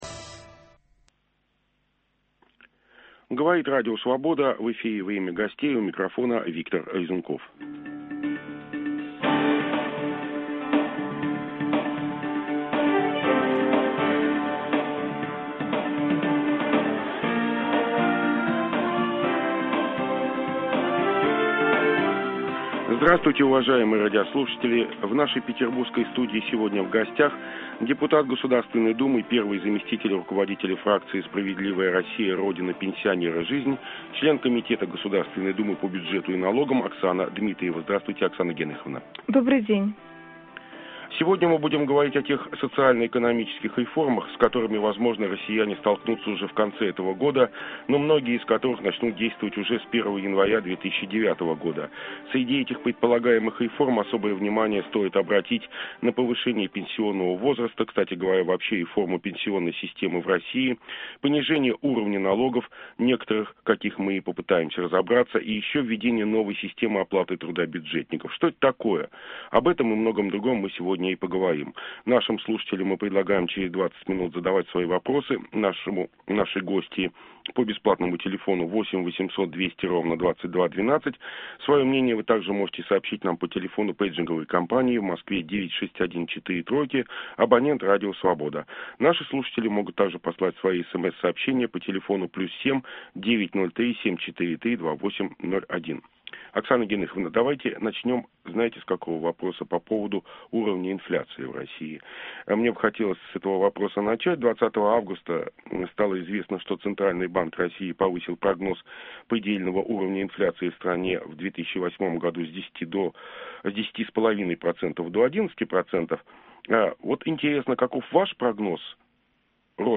Об этом и многом другом - в беседе с депутатом Государственной Думы / фракция "Справедливая Россия"/ Оксаной Дмитриевой.